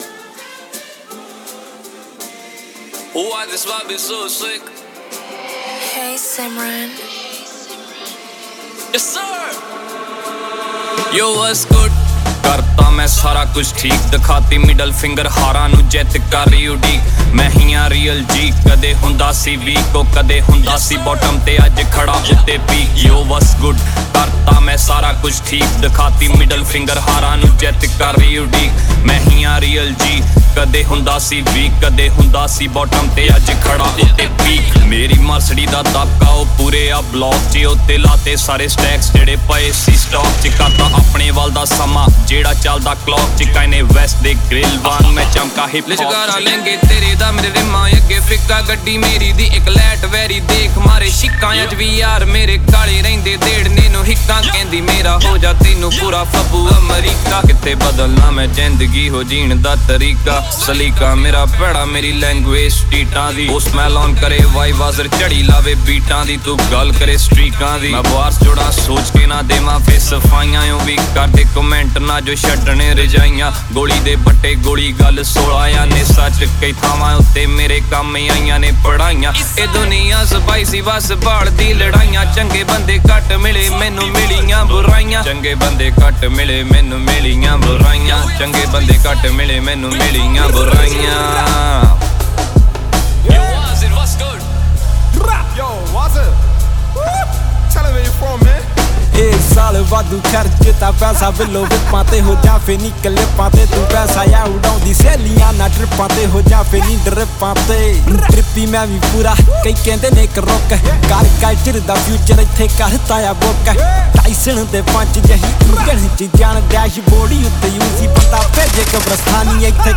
New Punjabi Song